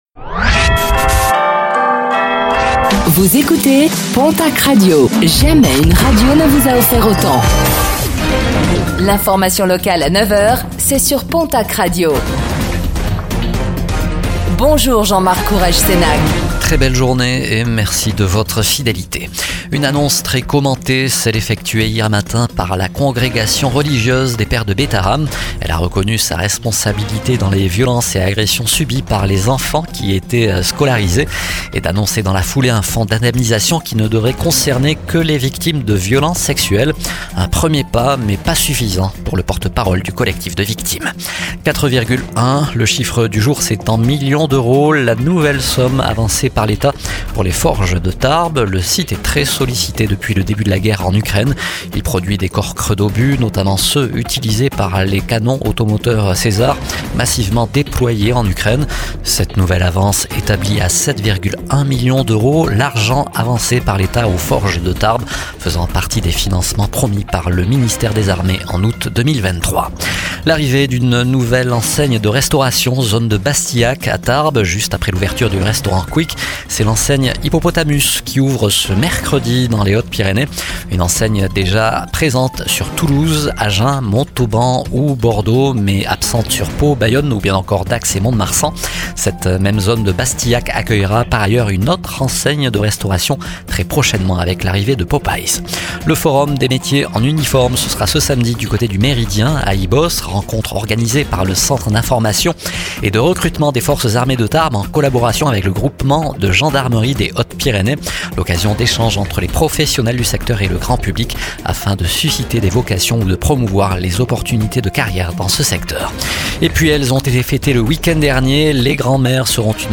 09:05 Écouter le podcast Télécharger le podcast Réécoutez le flash d'information locale de ce mercredi 05 mars 2025